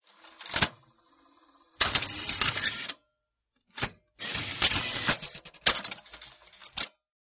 3 /4" Video tape deck tape loads and plays. Tape Loading Transport Engage Eject Tape